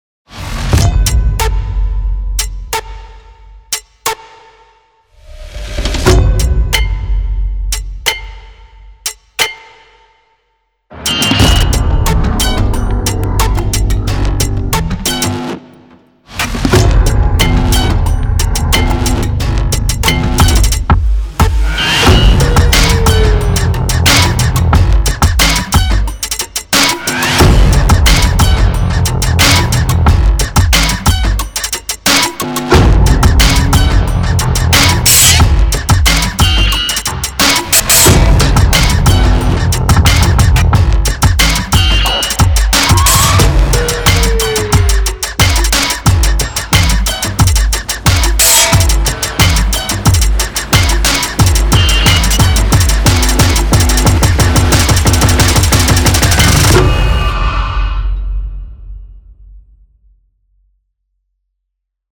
Atmospheric guitars, banjos, dobros, and mandolins
• Twisted guitar FX designed for tension compositions
Tense sounds for sound design